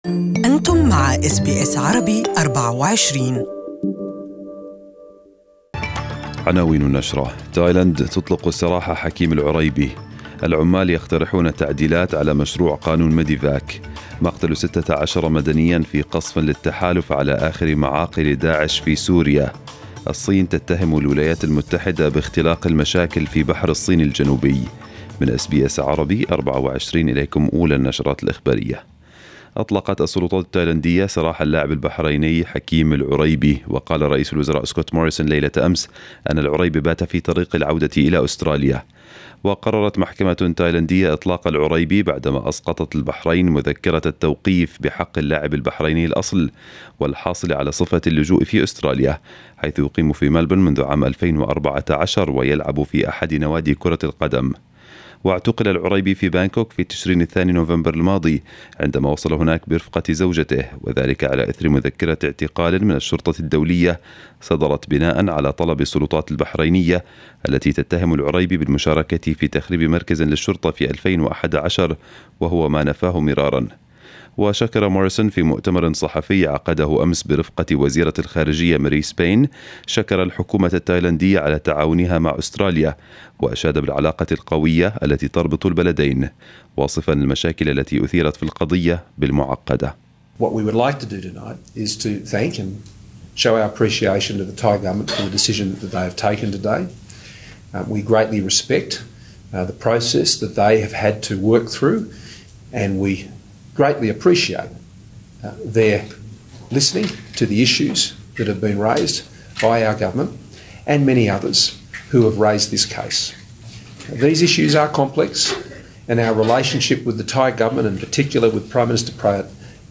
نشرة الأخبار المفصلة باللغة العربية